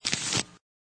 clickCard.ogg